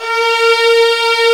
Index of /90_sSampleCDs/Roland L-CD702/VOL-1/CMB_Combos 1/CMB_Brite Strngs